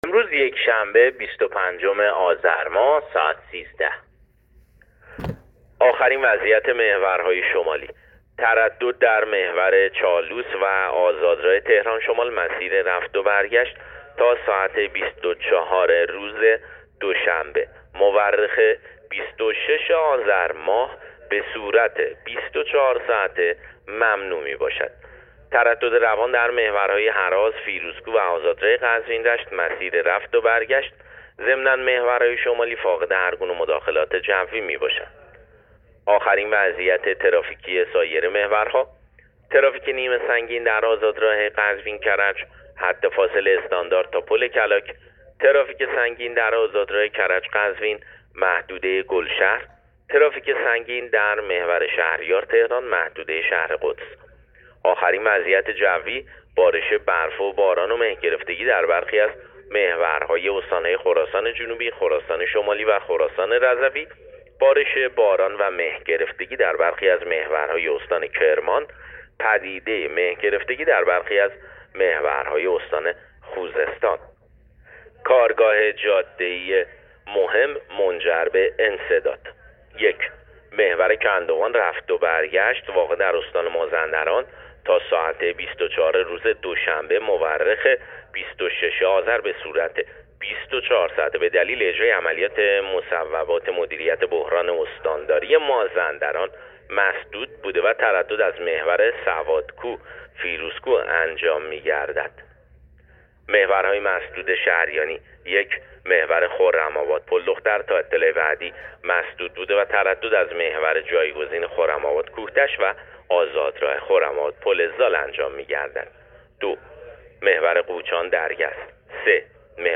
گزارش رادیو اینترنتی از آخرین وضعیت ترافیکی جاده‌ها تا ساعت ۱۳ بیست‌وپنجم آذر